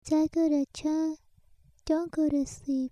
Specialties: Cute and soft young girls' voices, medium low to medium high ranged. Can sing, Alto.